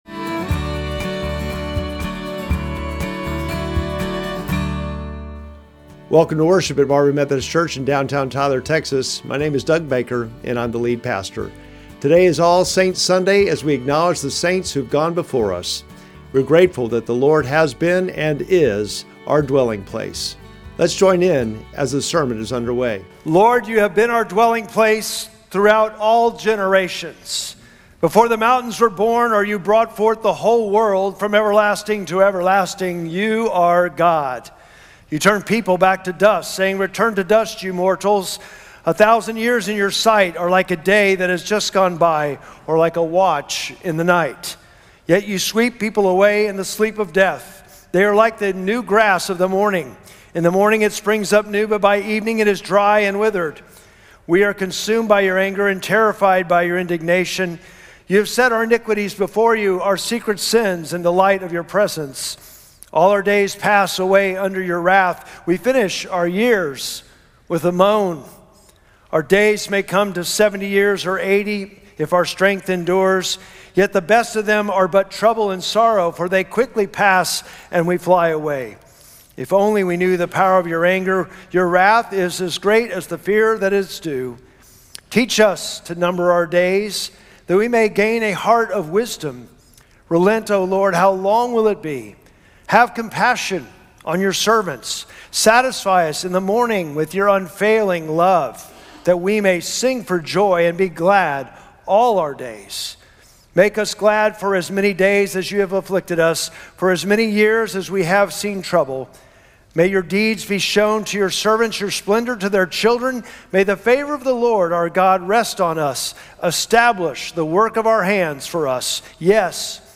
Sermon text: Paslm 90:1-7